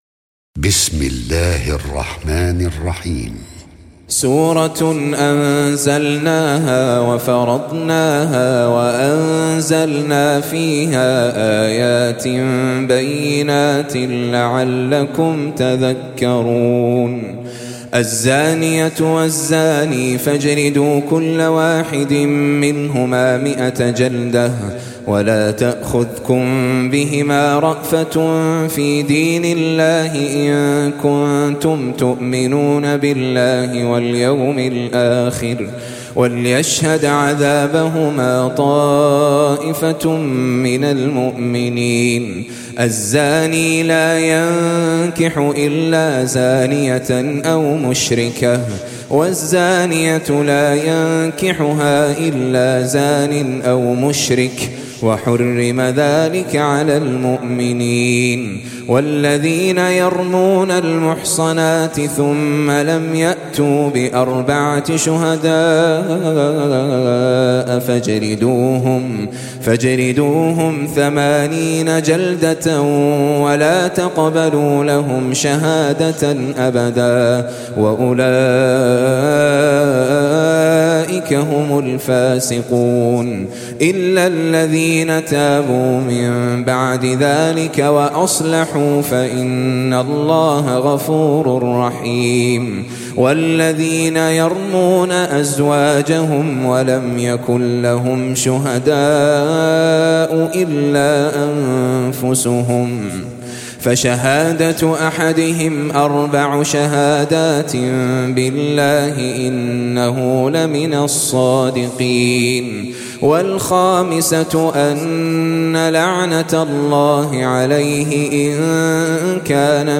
Surah Sequence تتابع السورة Download Surah حمّل السورة Reciting Murattalah Audio for 24. Surah An-N�r سورة النّور N.B *Surah Includes Al-Basmalah Reciters Sequents تتابع التلاوات Reciters Repeats تكرار التلاوات